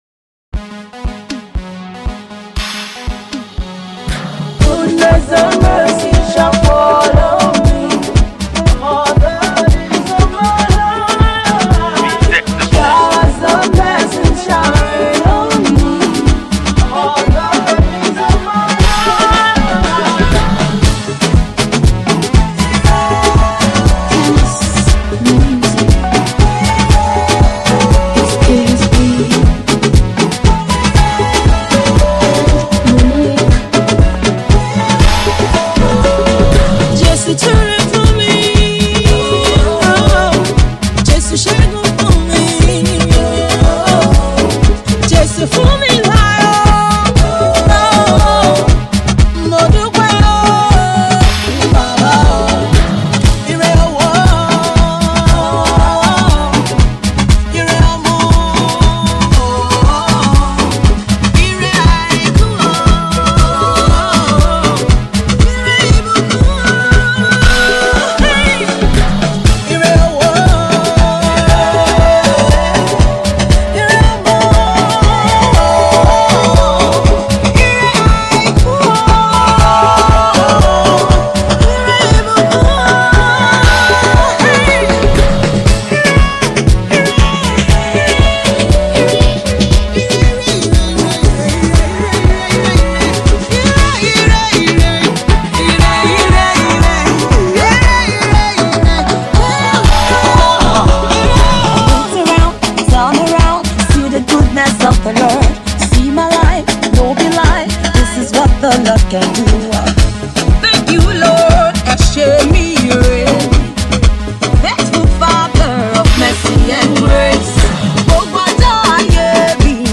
gospel music artist
high praise rhythm that will get you on your feet dancing